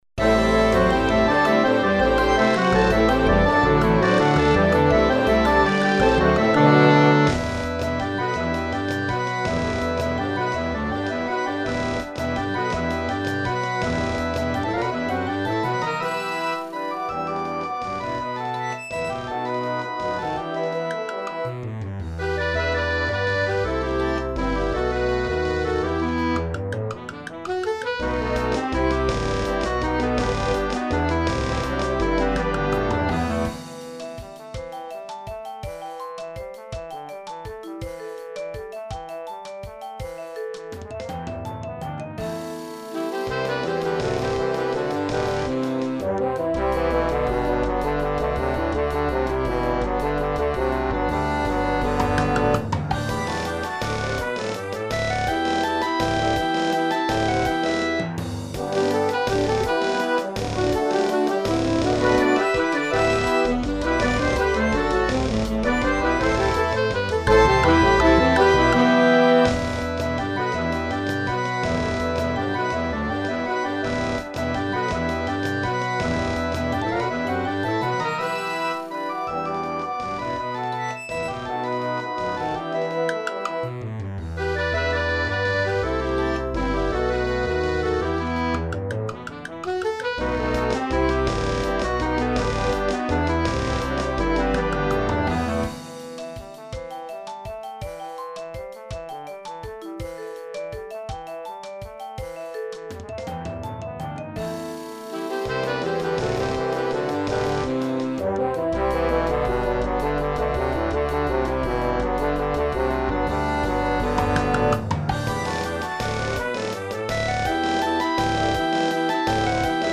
El objetivo de este trabajo es enriquecer el repertorio para el formato de Banda Sinfónica mediante la creación de una Suite Moderna Ecuatoriana Para Banda Sinfónica.
La Suite Para Banda Sinfónica será ejecutada en concierto público por la Banda Sinfónica Metropolitana de Quito (BSMQ) durante el segundo semestre del año 2012, se adjunta también una ejecución virtual por medios digitales para un mejor entendimiento de la partitura.